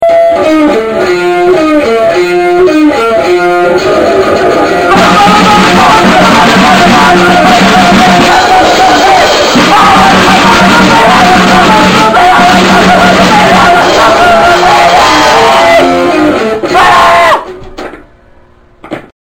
penn state's premiere grindcore band.